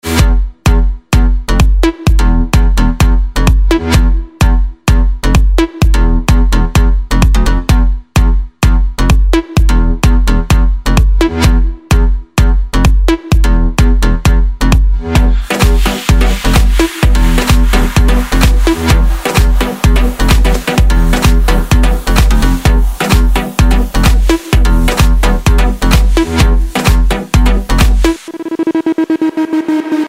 • Качество: 128, Stereo
electro